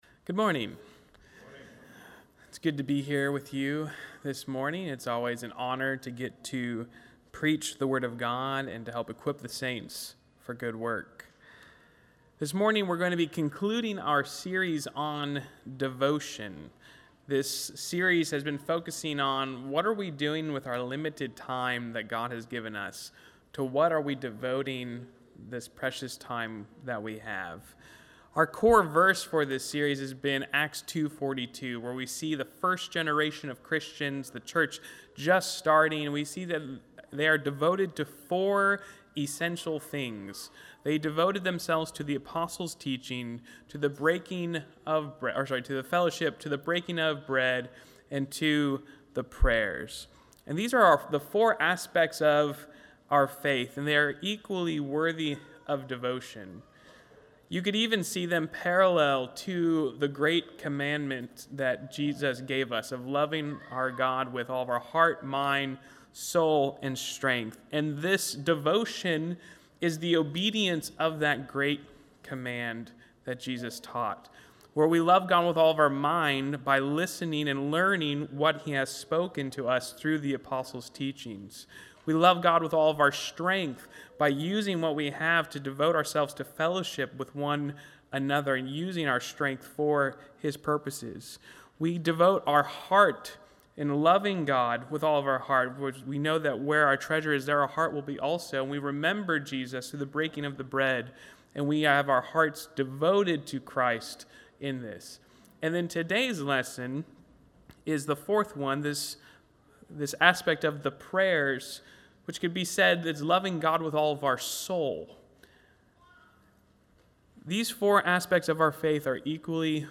Valley church of Christ - Matanuska-Susitna Valley Alaska
Audio Sermons Devotion #4